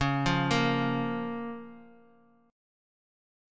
C#6 Chord
Listen to C#6 strummed